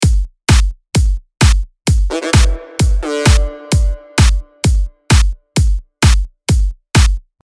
dTM講座　Electro House （エレクトロ ハウス）の作り方③
前回は２つの音を組み合わせました。